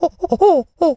pgs/Assets/Audio/Animal_Impersonations/monkey_chatter_13.wav at master
monkey_chatter_13.wav